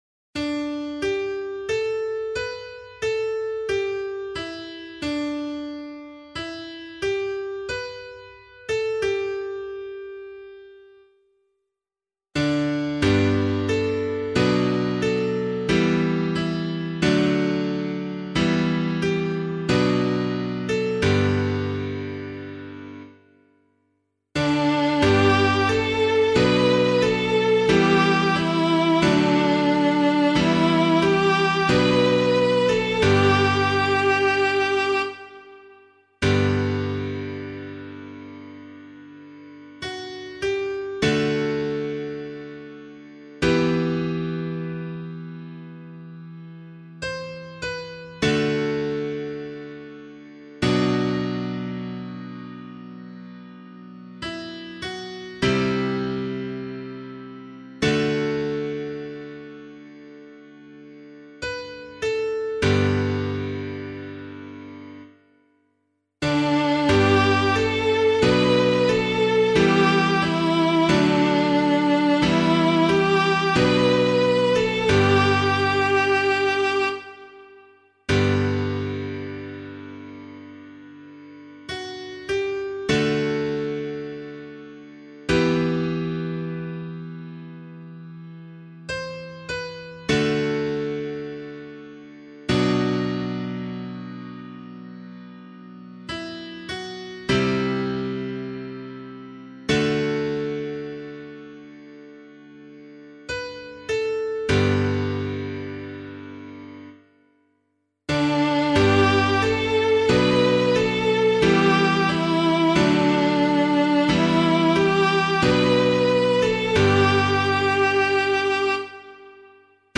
017 Lent 5 Psalm C [LiturgyShare 7 - Oz] - piano.mp3